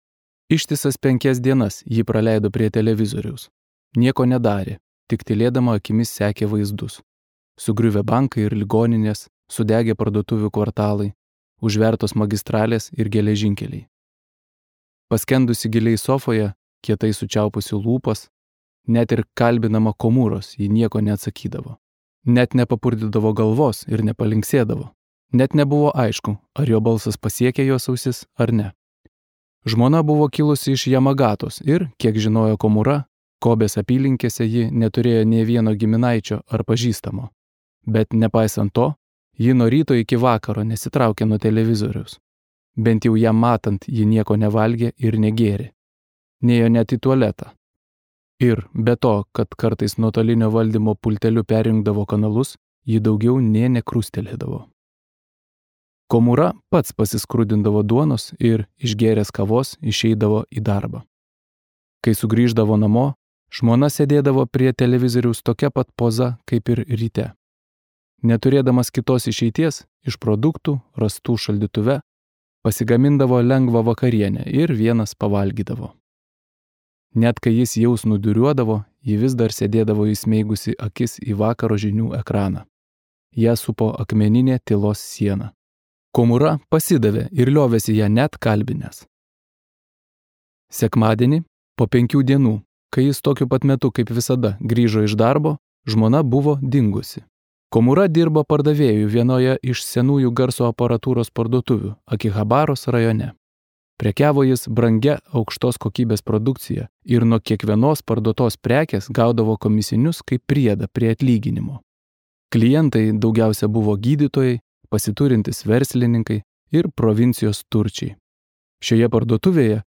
Audio Visi Dievo vaikai šoka